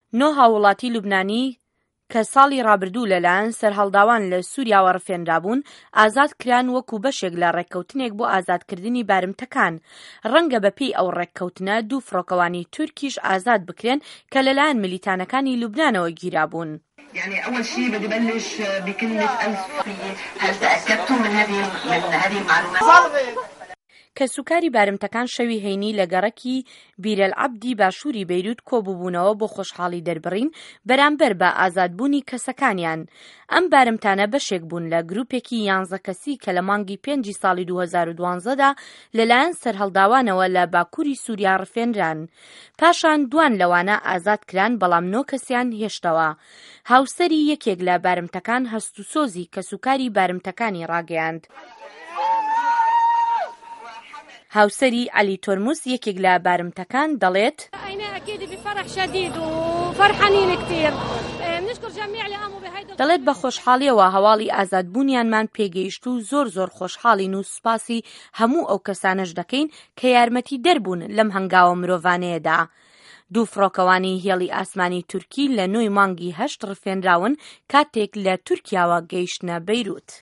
ڕاپۆرت له‌سه‌ بارمه‌تکانی لوبنانی